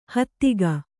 ♪ hattiga